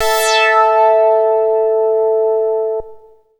69-MASS LEAD.wav